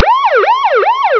Pack de 60 bruitages relatifs au transport au format WAV depuis les voiles qui claquent jusqu’aux sirène de la police.
Sirene-de-police.wav